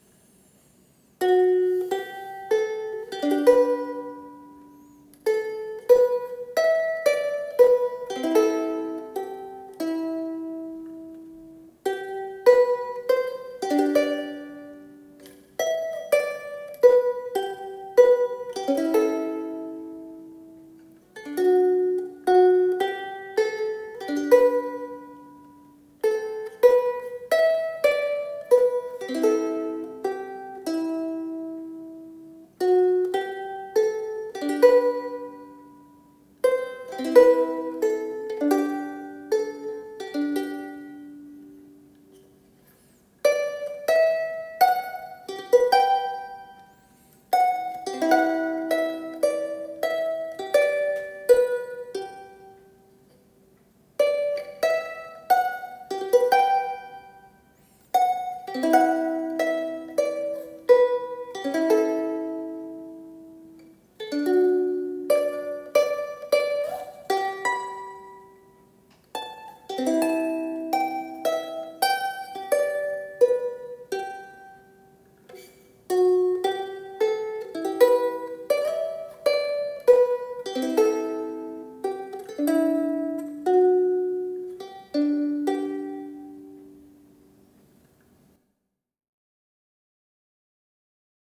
屋久桧と榧と栴檀と楓のウクレレ。コンサートサイズロングネック(テナーの弦長)。
表板が屋久桧(ヤクヒノキ)。
強い芯のある明るい音になりました。音量もサスティンもよし。